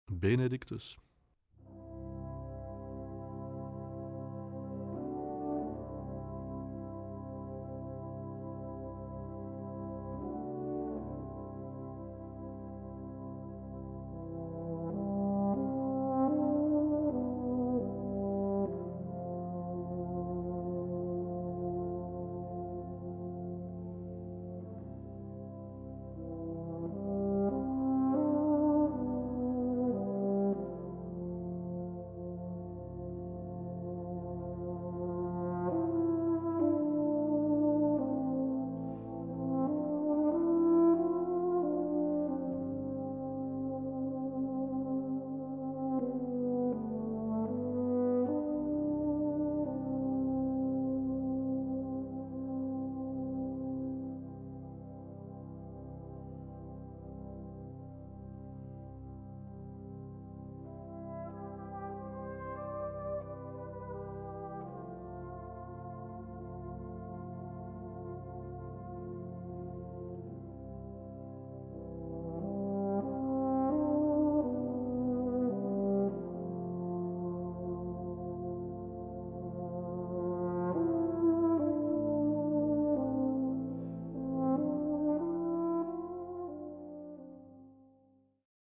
Gattung: Solo für Euphonium und Blasorchester
Besetzung: Blasorchester